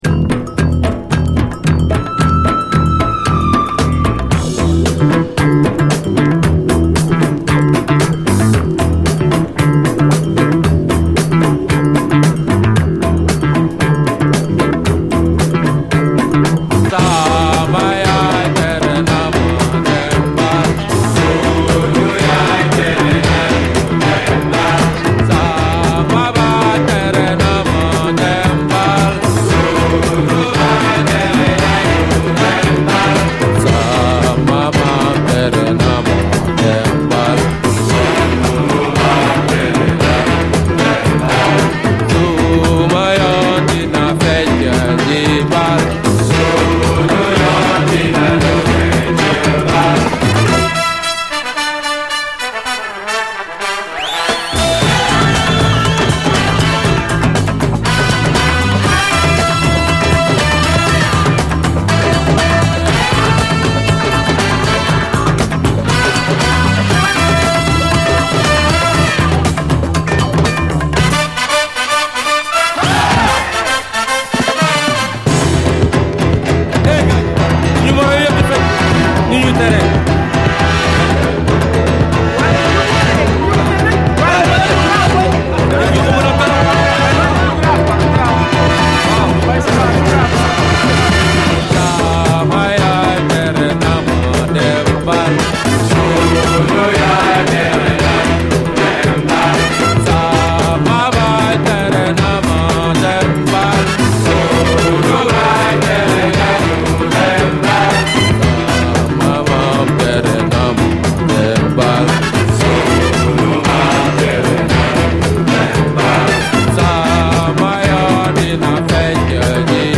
SOUL, 70's～ SOUL, AFRO, WORLD, 7INCH
ブラスが派手に炸裂するフランス産フェイク・アフロ・ファンク！
フランス産アフロ・ファンク！